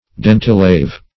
Search Result for " dentilave" : The Collaborative International Dictionary of English v.0.48: Dentilave \Den"ti*lave\, n. [L. dens, dentis, tooth + lavare to wash.] A wash for cleaning the teeth.